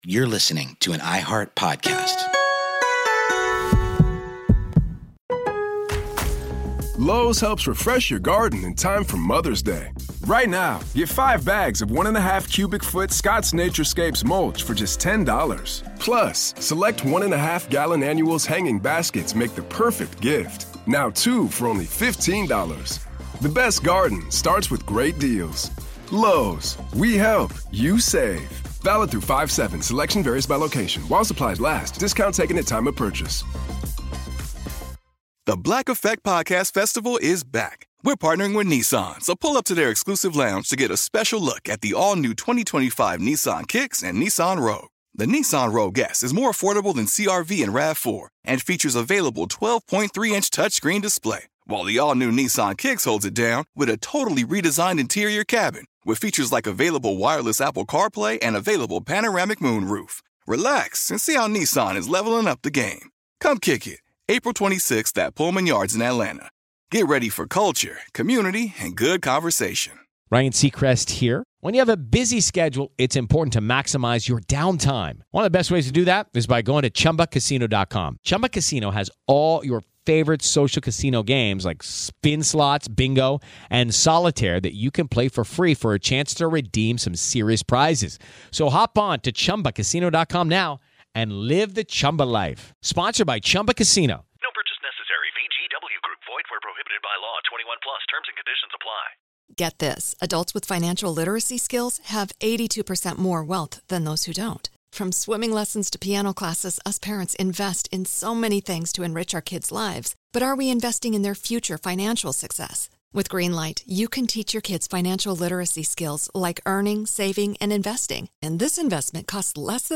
A special thanks to the National WWII Museum in New Orleans for providing this archival audio.